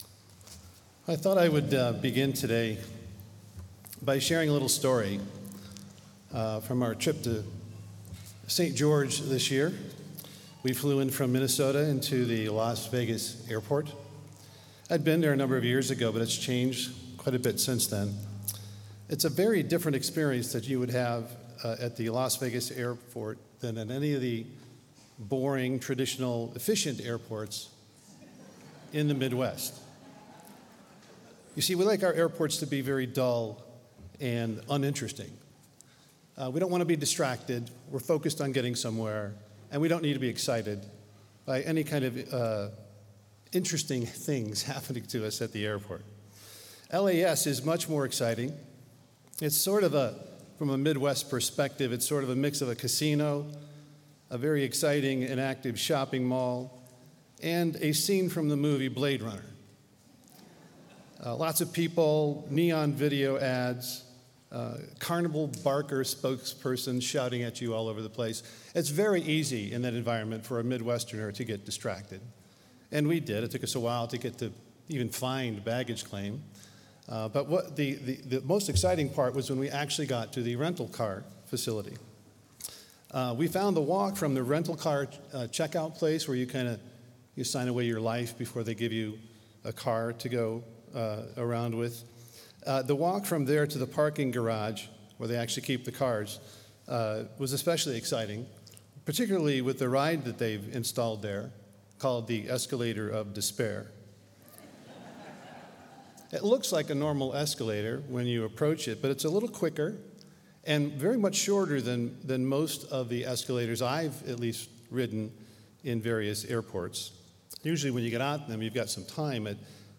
This sermon was given at the St. George, Utah 2022 Feast site.